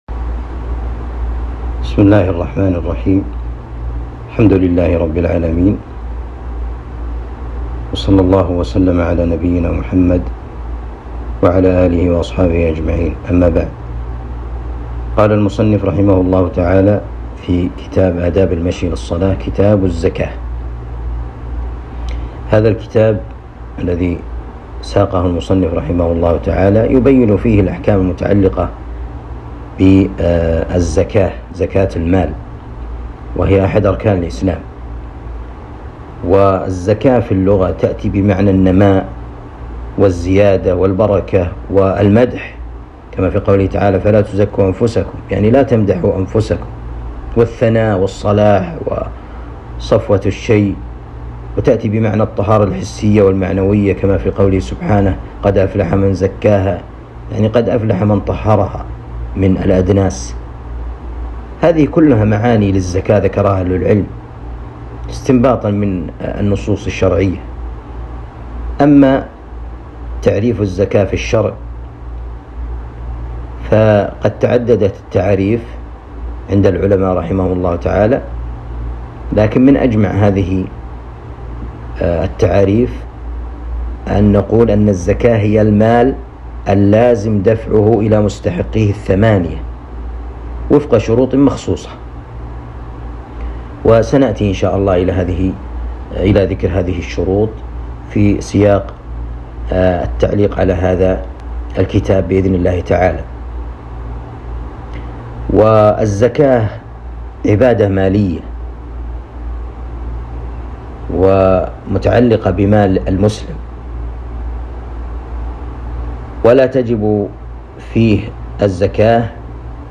الدروس شرح كتاب آداب المشي إلى الصلاة